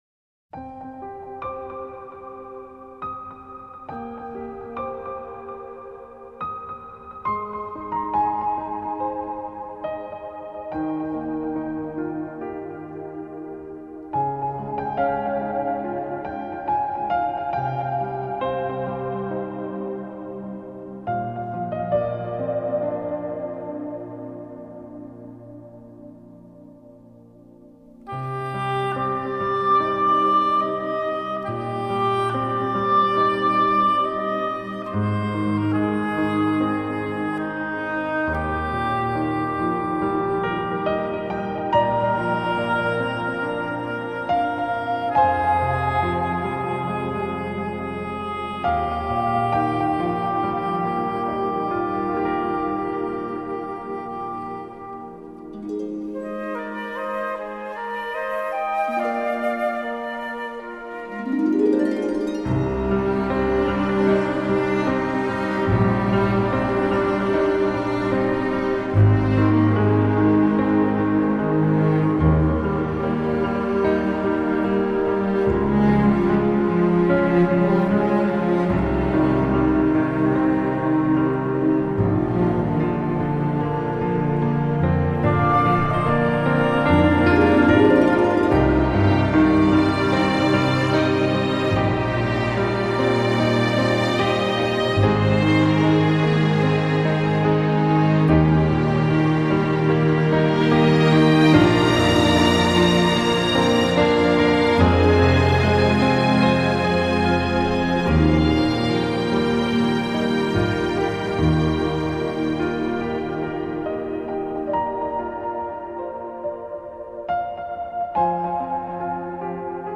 天籁钢琴, 经典配乐 你是第11169个围观者 0条评论 供稿者： 标签：, ,